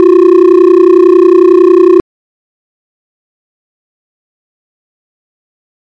旧玩具钢琴音符
Tag: 玩具 乐器 老旧 古典的